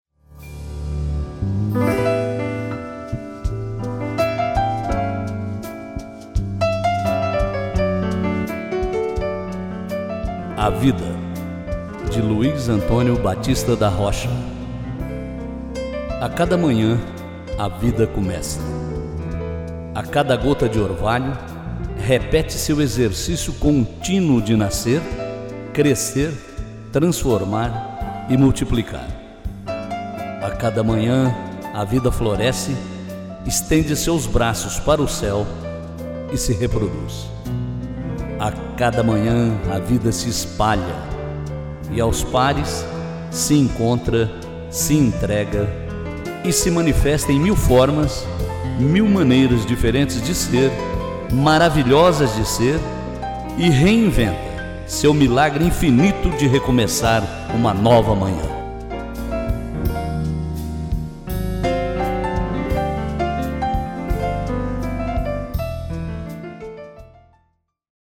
interpretação